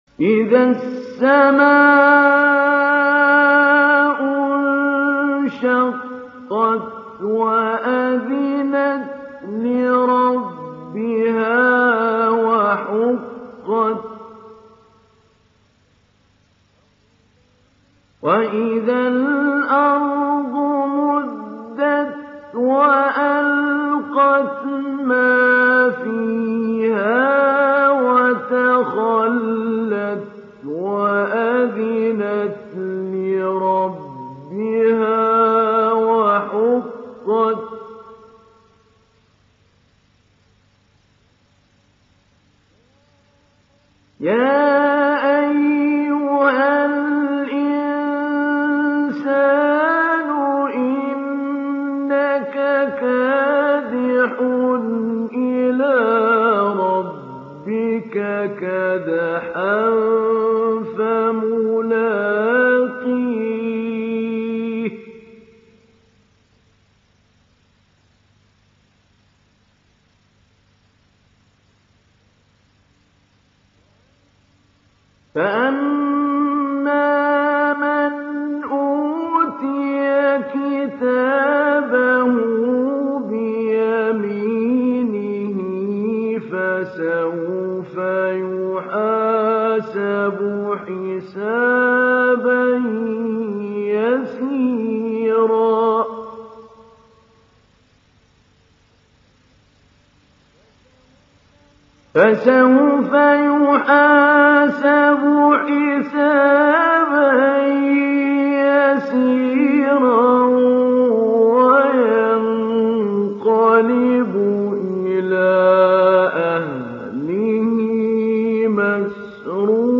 تحميل سورة الانشقاق mp3 محمود علي البنا مجود (رواية حفص)
تحميل سورة الانشقاق محمود علي البنا مجود